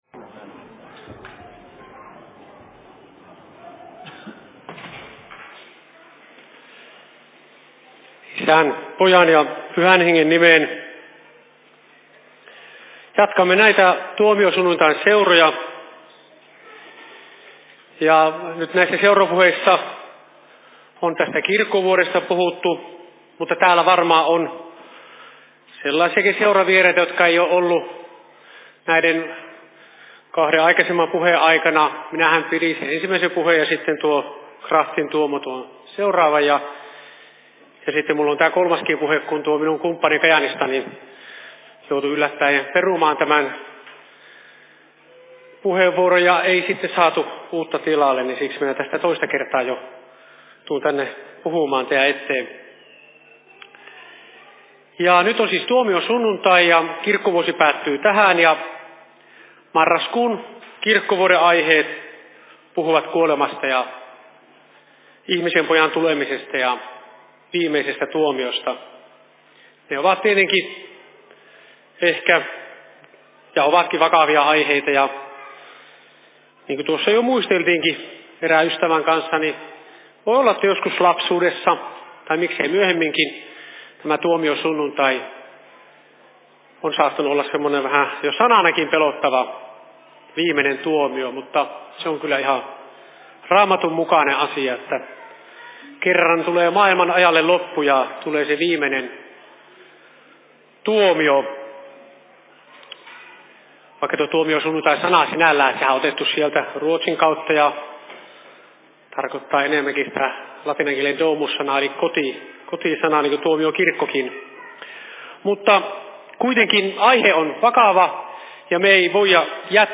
Seurapuhe Kuopion RY:llä 20.11.2022 17.00
Paikka: Rauhanyhdistys Kuopio